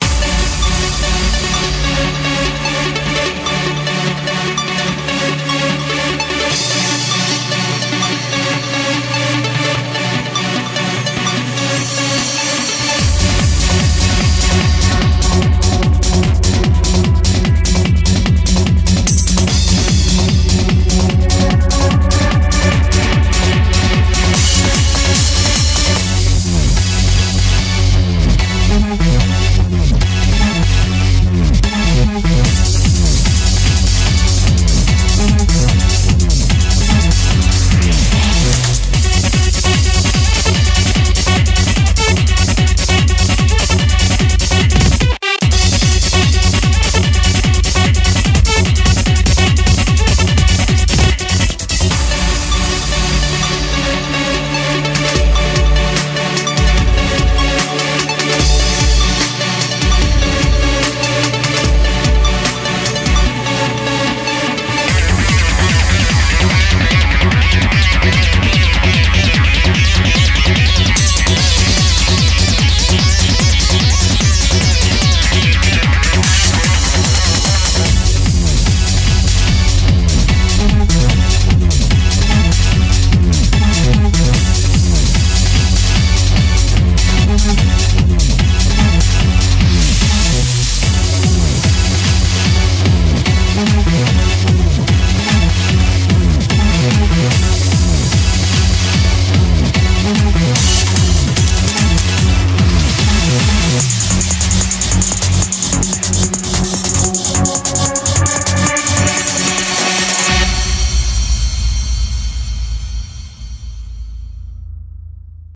BPM74-148
Audio QualityPerfect (High Quality)
Commentaires[TECHNO]